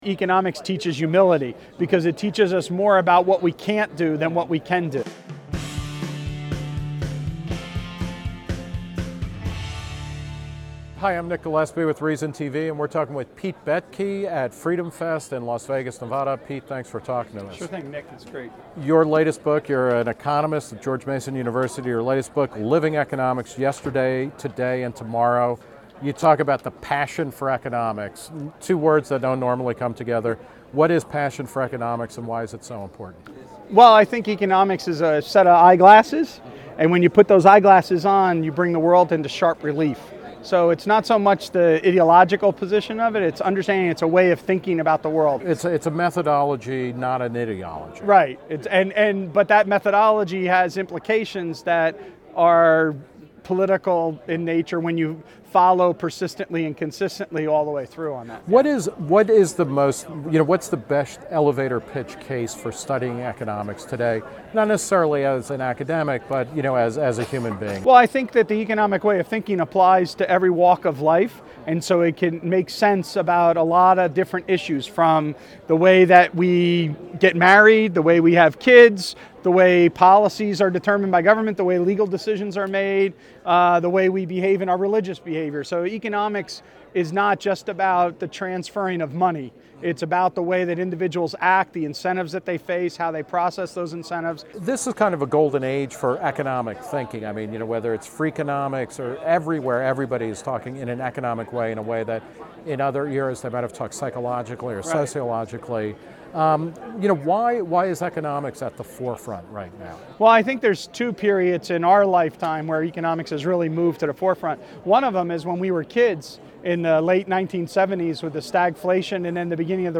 Held each July in Las Vegas, FreedomFest is attended by around 2,000 limited-government enthusiasts and libertarians.